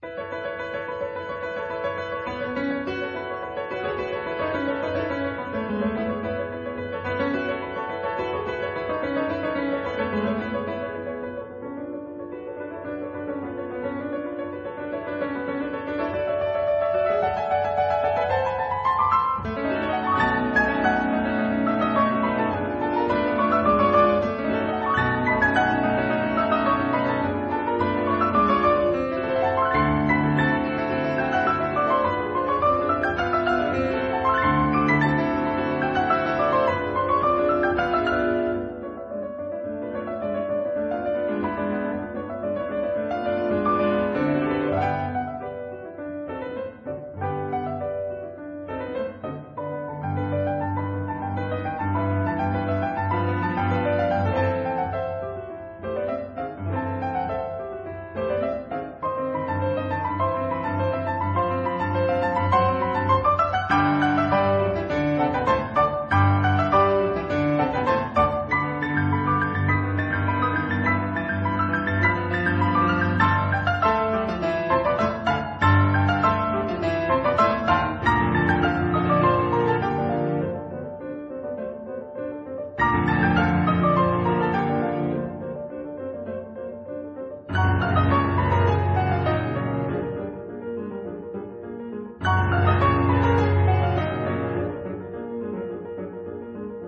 他的音色相當乾淨，有種去除了雜質後的純淨。